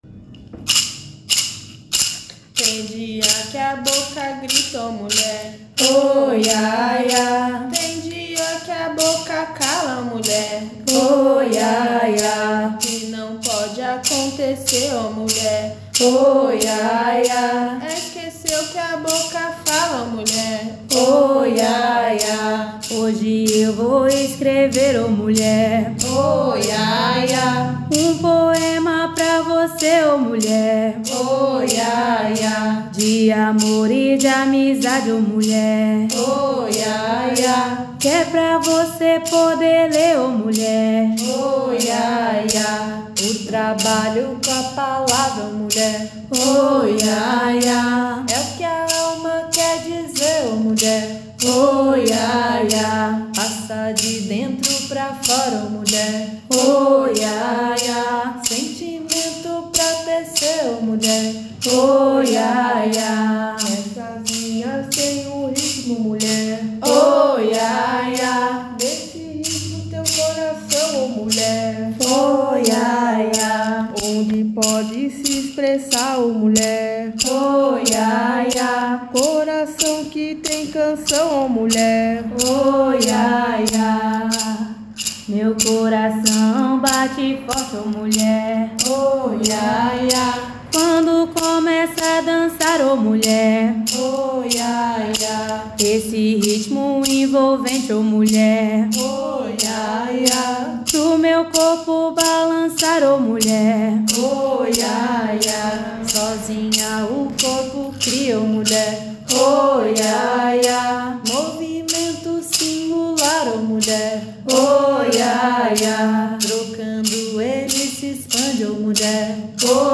poemas-ritmados.mp3